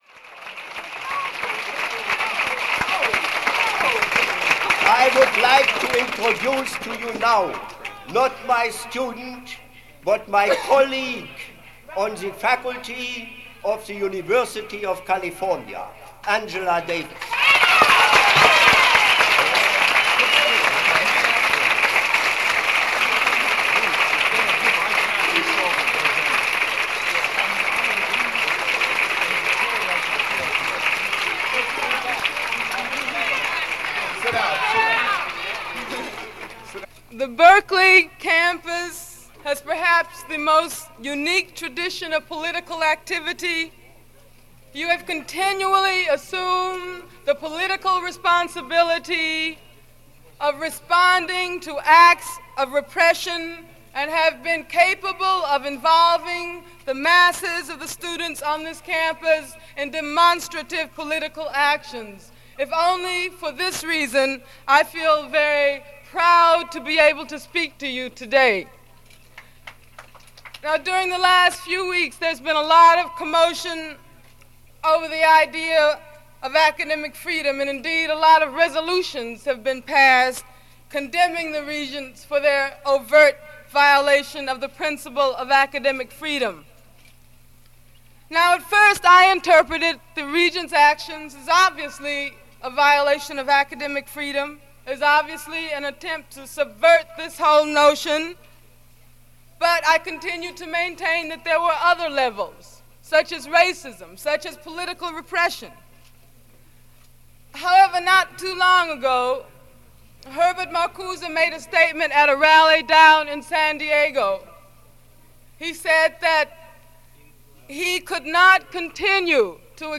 Angela Davis – Address at UC Berkeley – introduced by Herbert Marcuse – 1969
Angela-Davis-UC-Berkeley-1969.mp3